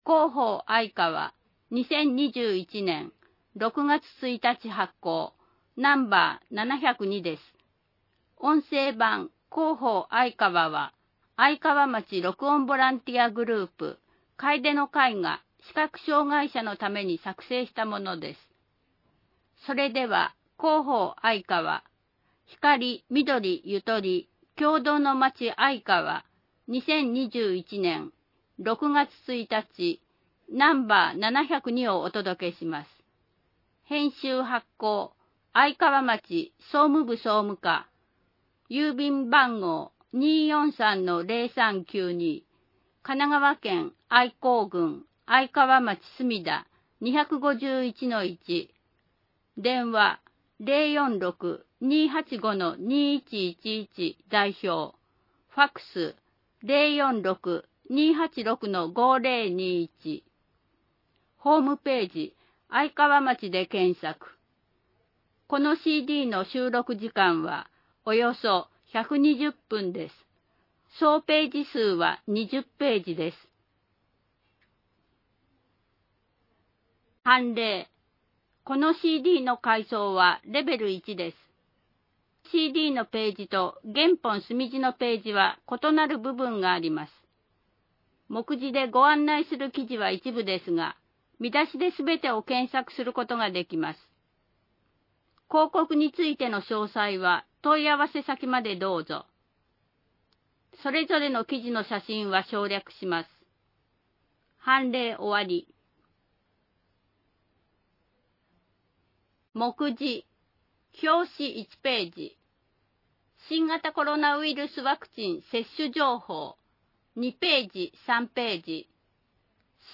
町の助成制度 (PDFファイル: 571.9KB) インフォメーション (PDFファイル: 960.5KB) 愛川トピックス (PDFファイル: 546.6KB) あいかわカレンダー (PDFファイル: 401.4KB) 音声版「広報あいかわ」 音声版「広報あいかわ」は、「愛川町録音ボランティアグループ かえでの会」の皆さんが、視覚障がい者の方々のために「広報あいかわ」を録音したものです。